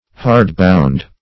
\hard"cov*er\ hard-bound \hard"-bound`\adj.